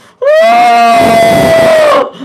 Swat Crying Sound Effect Download: Instant Soundboard Button
Reactions Soundboard1 views